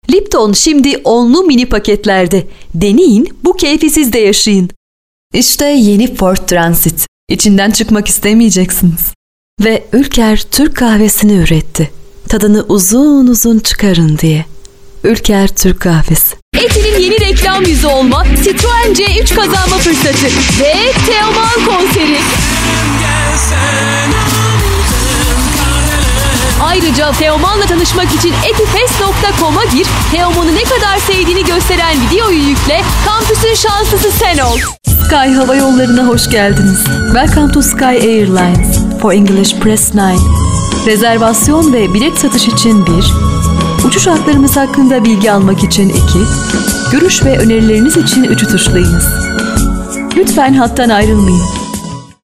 Hello I am professional Turkish voice artist.
I have my own recordig studio.
Kein Dialekt
Sprechprobe: Werbung (Muttersprache):